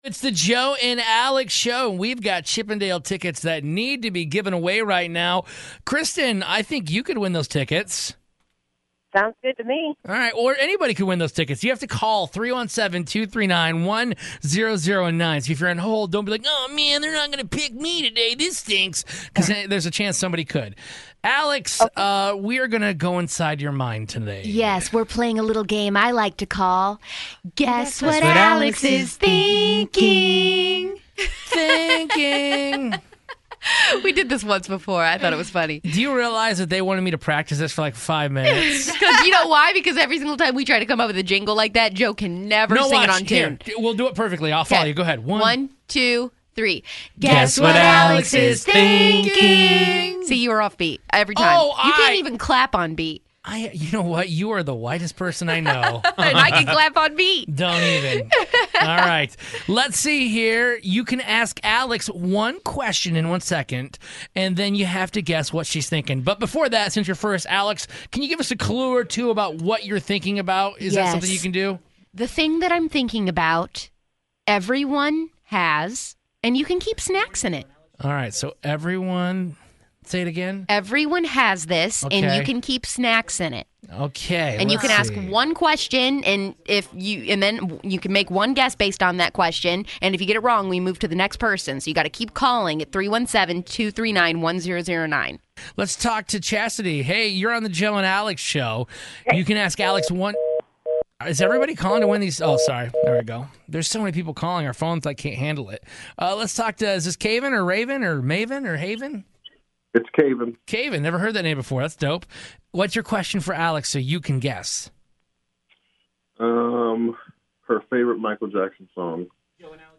and each caller has one question to ask to help them guess what it is. If they guess wrong they are OUT and they don't win the tickets to the Chippendales.